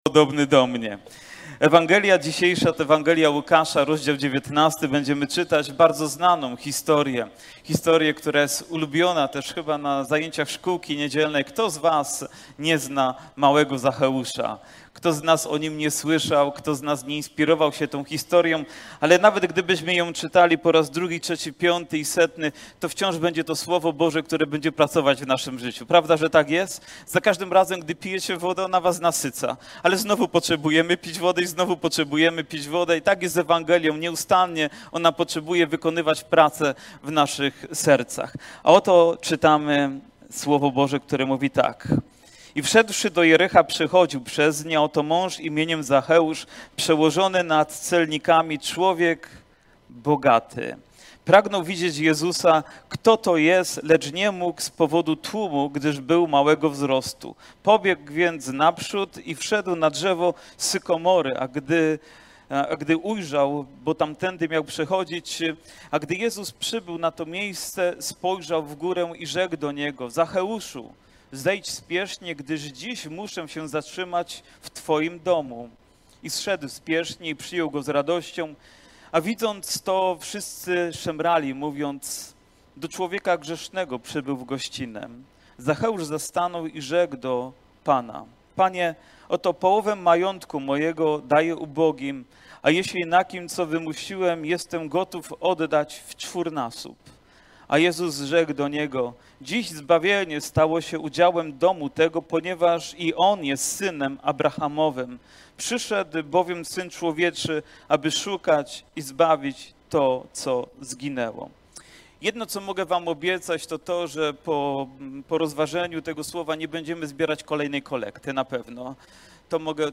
Kazania , Nabożeństwo niedzielne